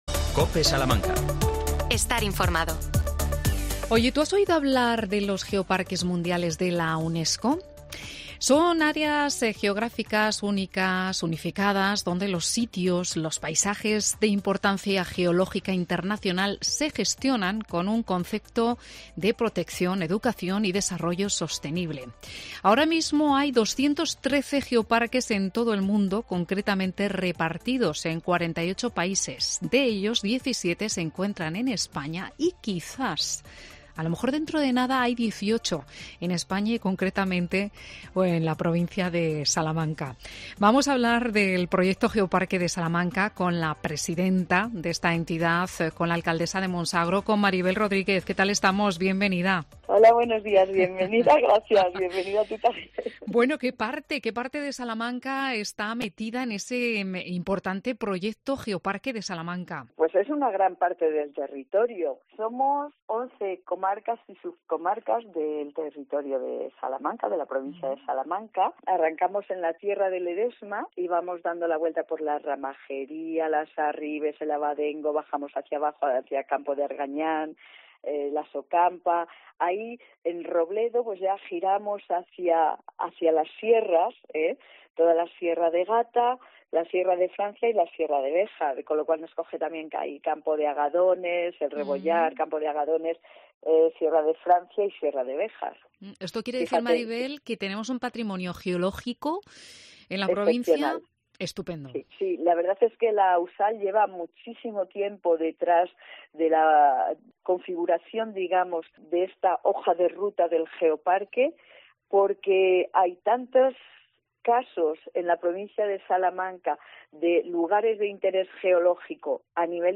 La iniciativa abarca un vasto territorio que incluye 110 pueblos de once comarcas y subcomarcas, según ha explicado la presidenta de la entidad y alcaldesa de Monsagro, Maribel Rodríguez, en una entrevista en COPE Salamanca.